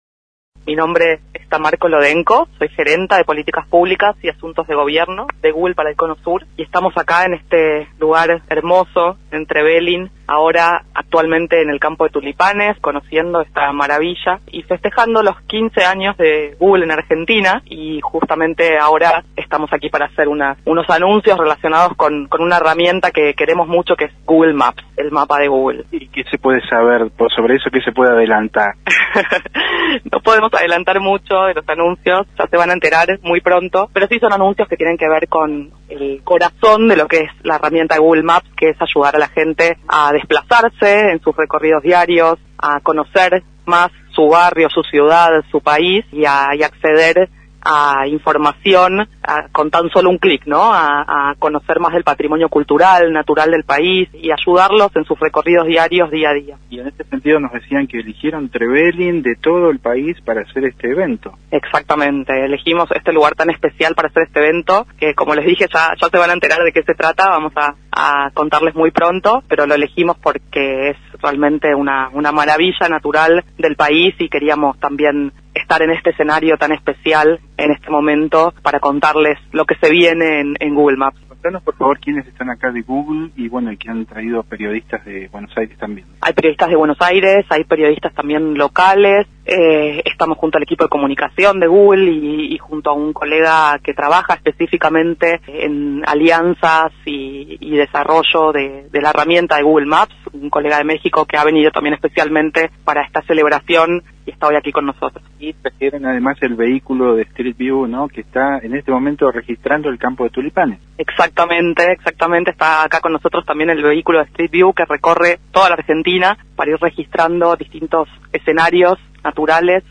La compañía que es una de las más importantes del mundo en productos y servicios relacionados con internet, eligió Trevelin para la presentación de novedades de Google Maps y Street View.